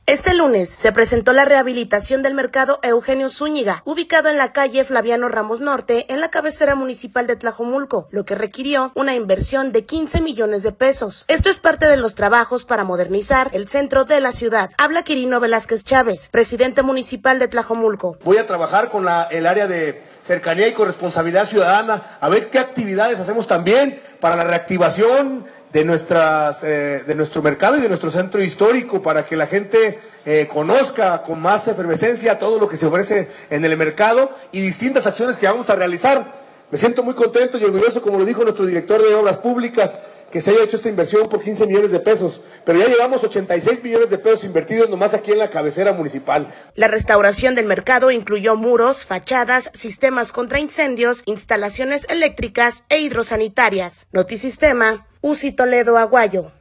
Habla Quirino Velázquez Chávez, presidente municipal de Tlajomulco.